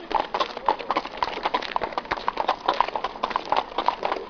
جلوه های صوتی
دانلود صدای حیوانات جنگلی 6 از ساعد نیوز با لینک مستقیم و کیفیت بالا